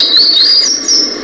Basileuterus culicivorus - Arañero común
aranierocomun.wav